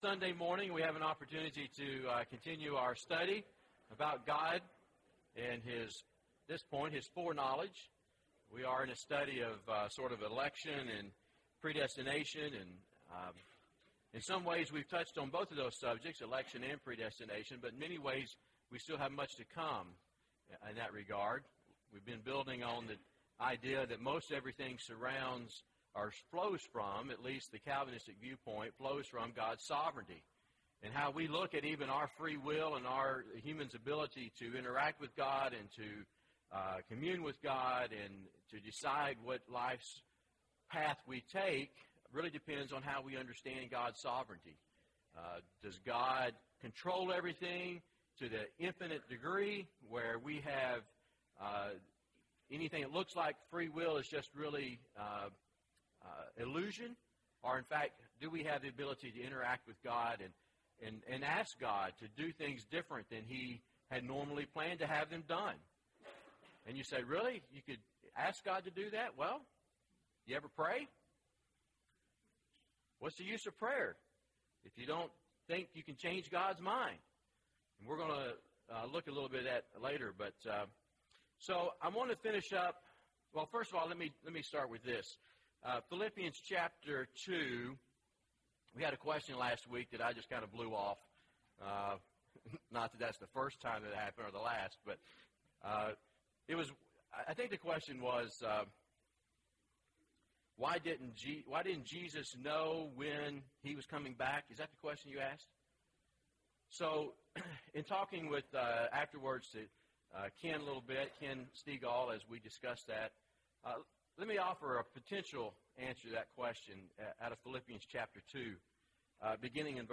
Sunday AM Bible Class